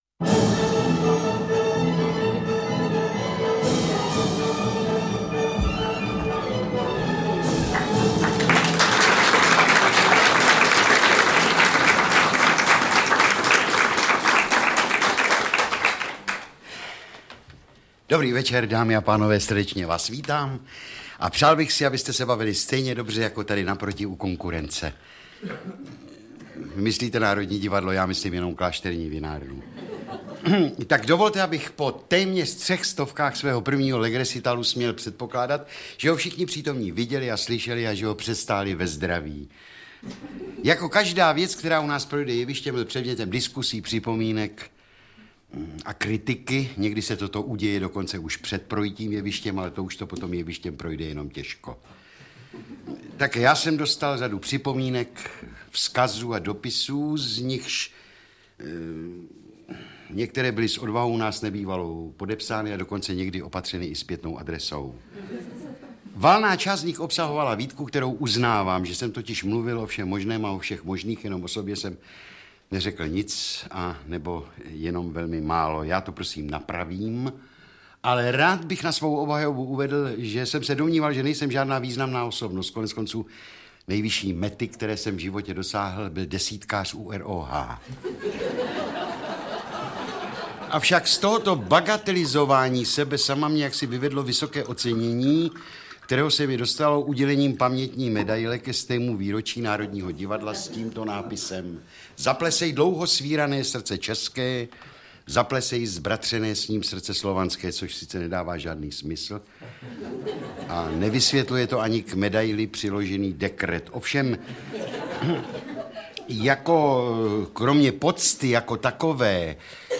Interpret:  Bohumil Bezouška
audiokniha obsahuje humorné povídky a příběhy Bohumila Bezoušky.Natočeno v lednu 1987 v pražském Divadle Viola.
AudioKniha ke stažení, 1 x mp3, délka 1 hod. 16 min., velikost 69,3 MB, česky